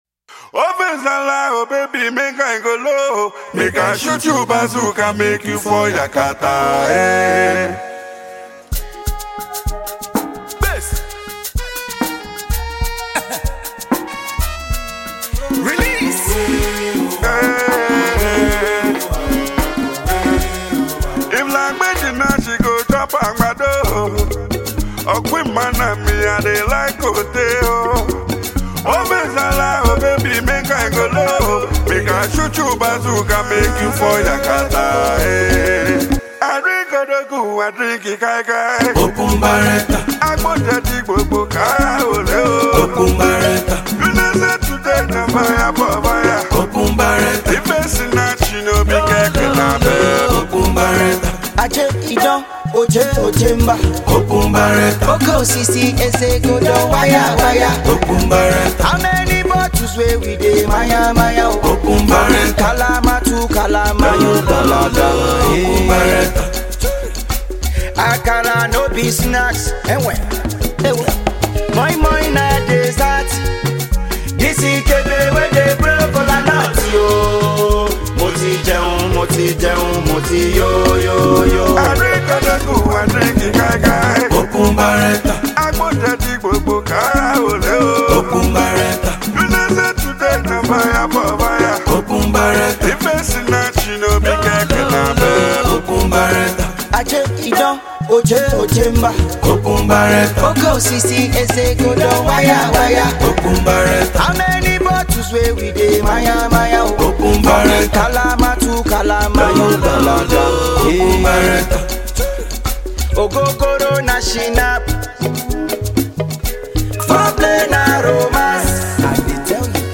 Prominent Nigerian Singer